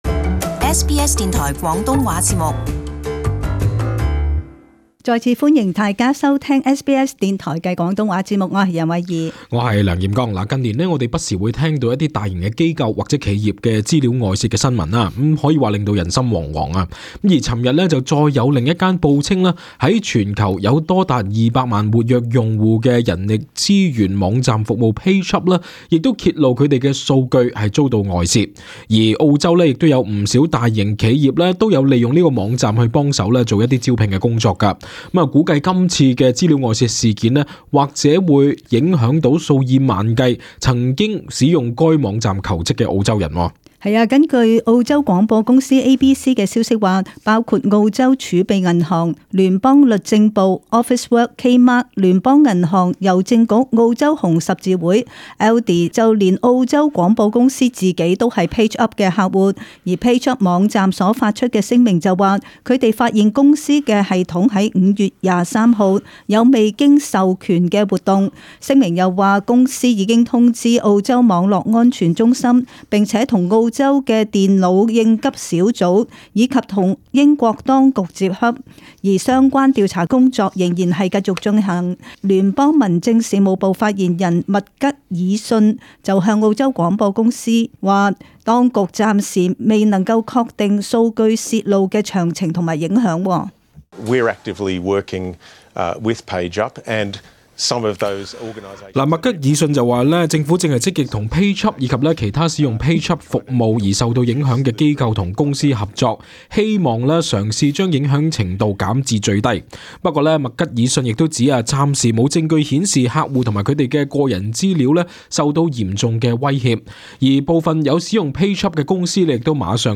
【時事報導】PageUp數據外洩過萬澳洲人或受影響